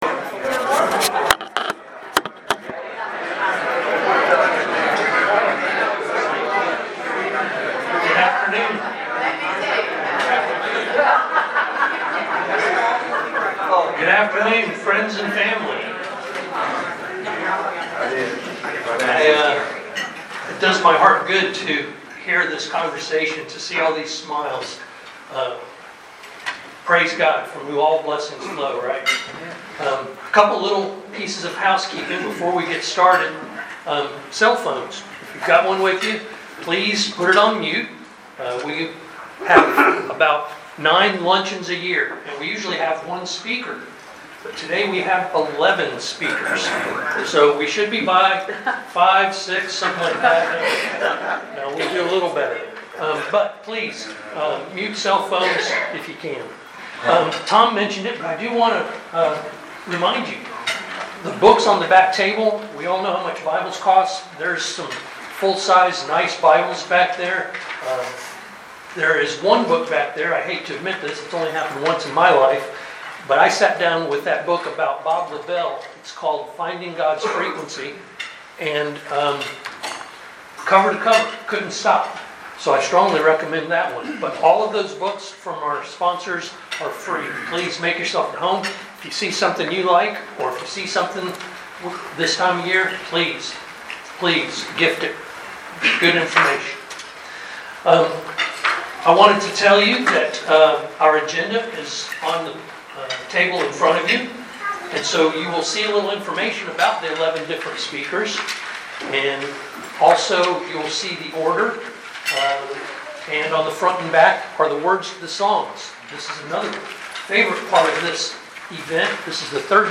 CBMC Chattanooga - Christmas Luncheon 2025.MP3